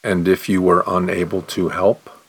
09_question_slow.mp3